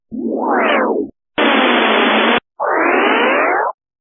On peut reconnaître des formes simples : Formes géométriques simples